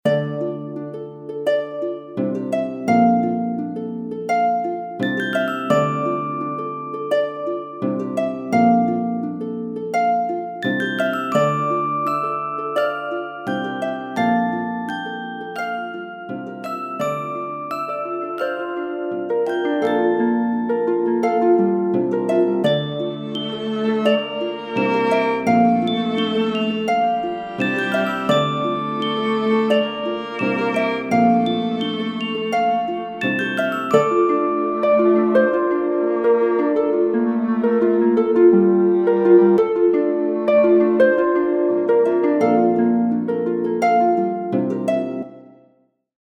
Resource 5: Music - Magical